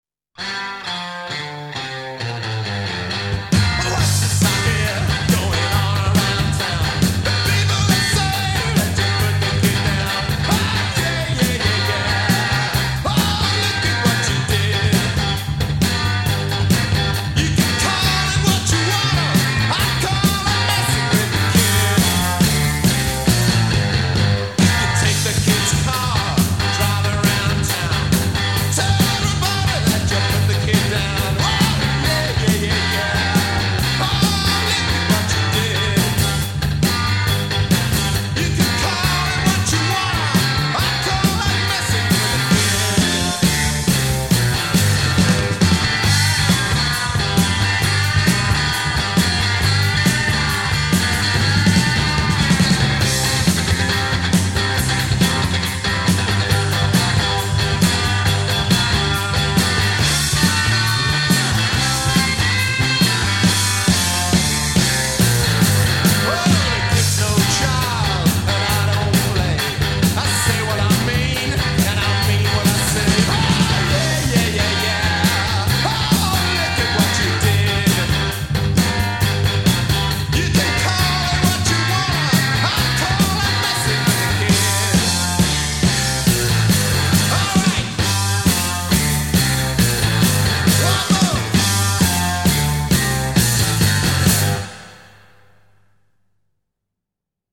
'staccato' playing